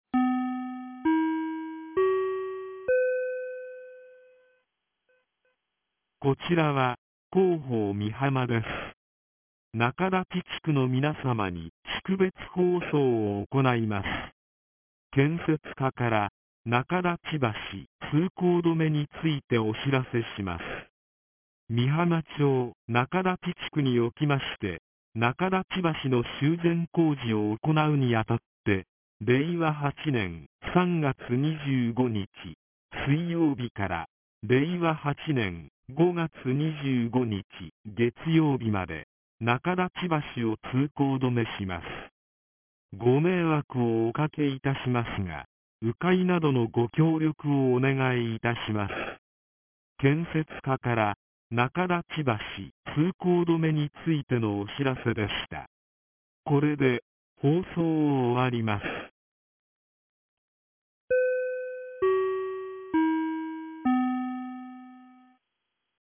■防災行政無線情報■ | 三重県御浜町メール配信サービス